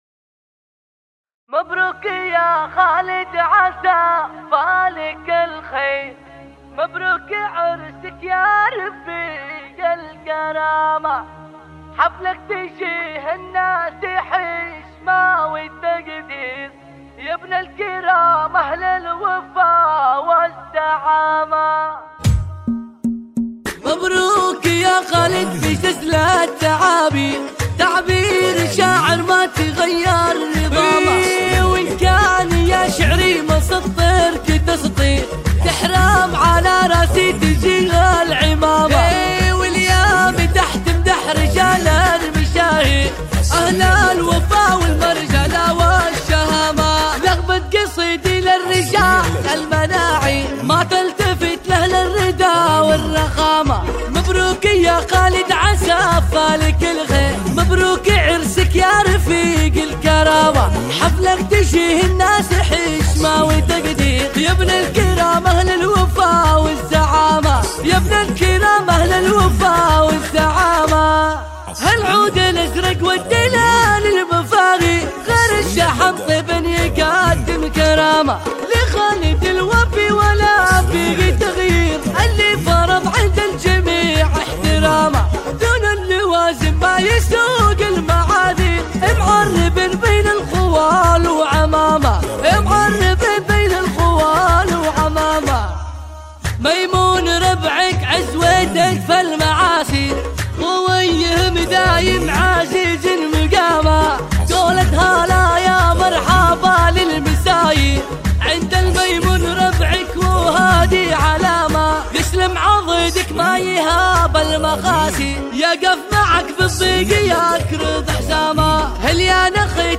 زفات 2025